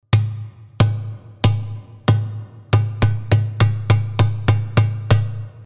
doum.mp3